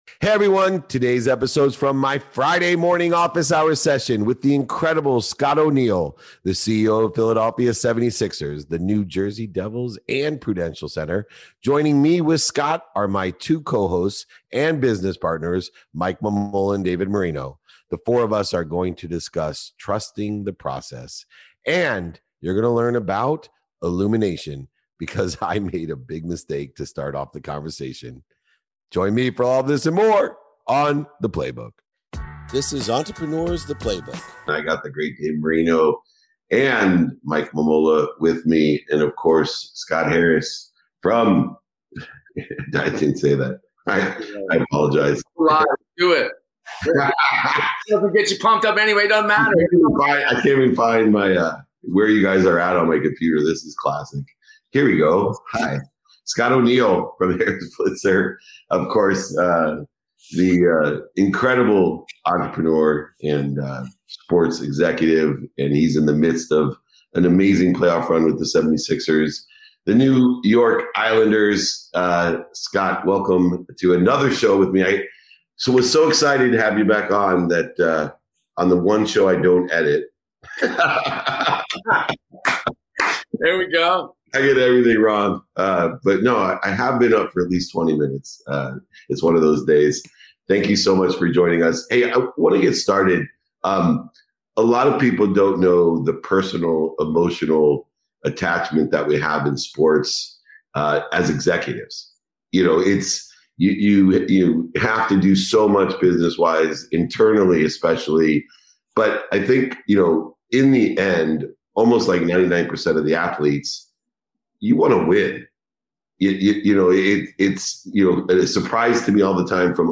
The four of us are going to discuss Trusting the Process and you are going to learn about illumination because I made a big mistake to start off the conversation.